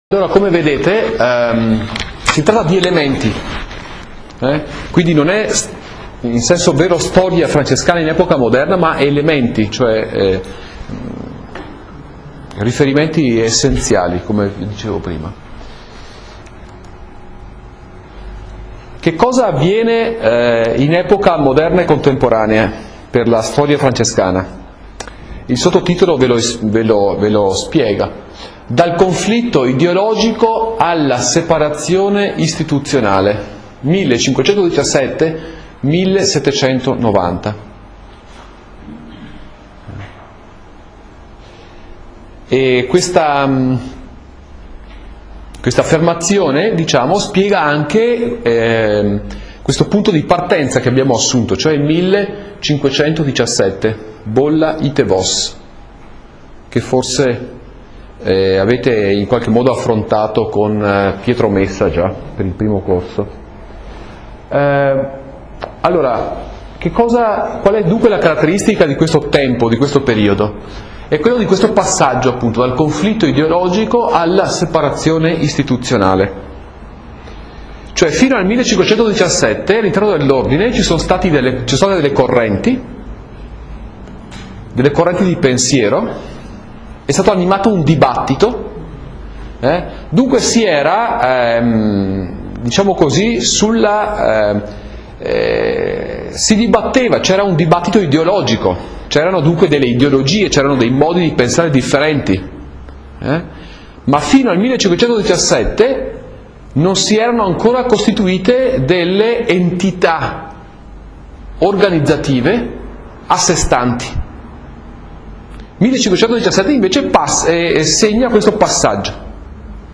In Italian: 05� lezione - 15 marzo 2010